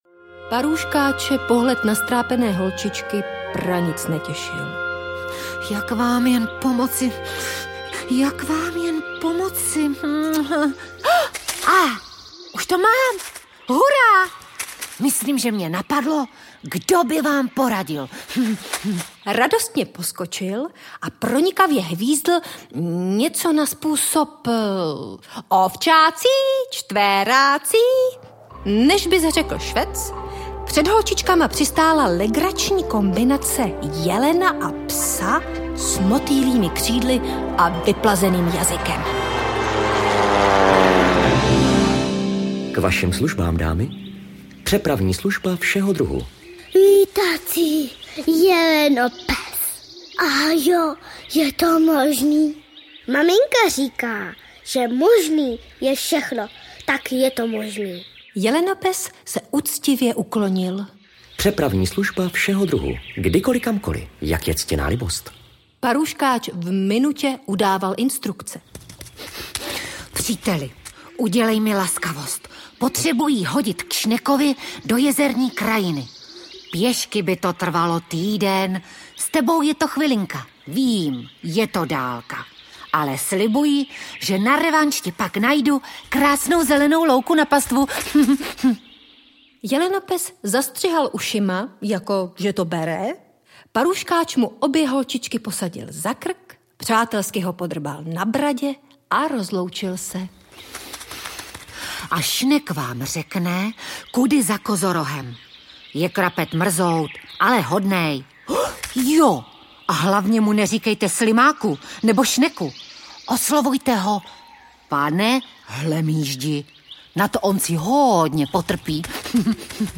Ve znamení motýla audiokniha
Pohádka s písničkami na motivy stejnojmenné knížky herečky Zdeňky Žádníkové, vás zavede do světa plného veselých melodií, dětské odvahy, kouzel a legrace.